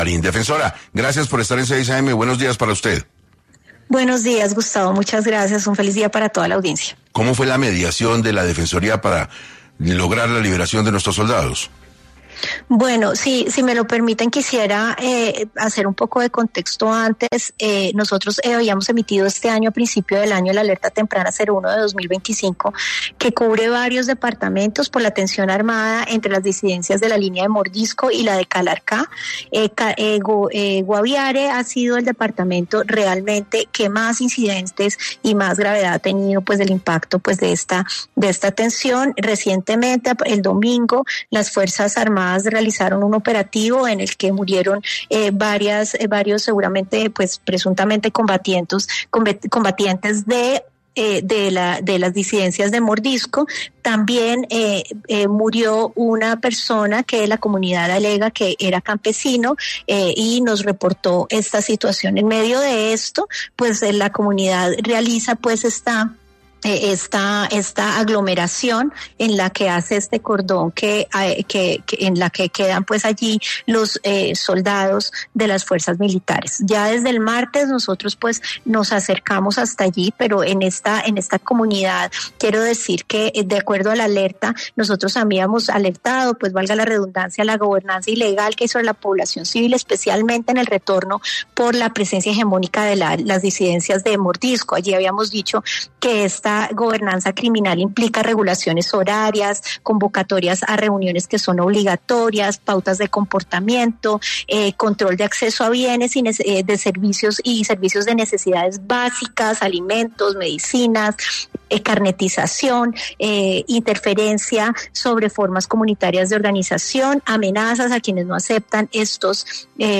La defensora del Pueblo, Iris Marín, habló en 6AM de Caracol Radio sobre la liberación de los 33 militares y las alertas activas que hay en esta región.